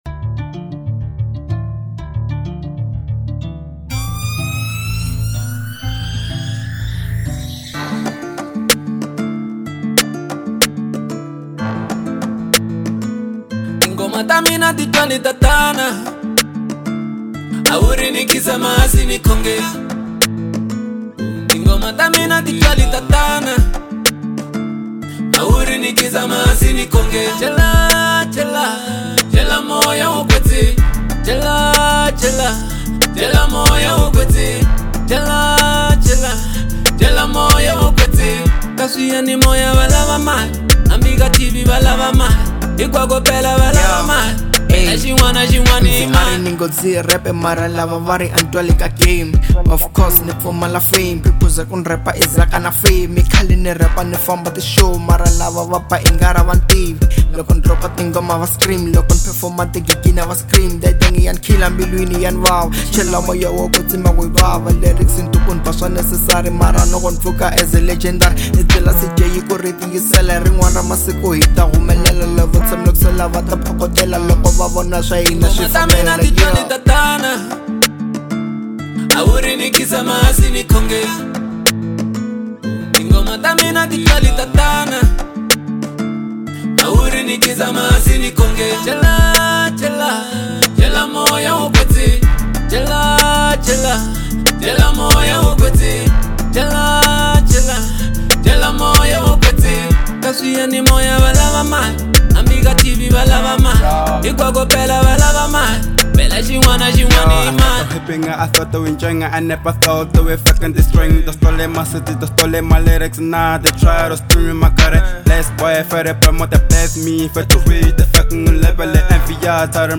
02:21 Genre : Trap Size